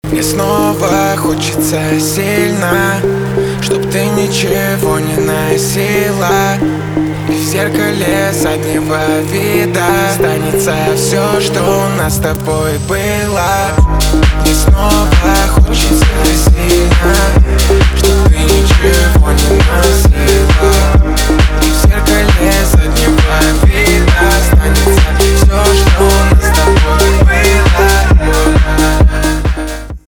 русский рэп , ремиксы , битовые , басы , качающие